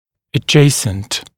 [ə’ʤeɪsnt][э’джейснт]расположенный рядом, смежный, соседний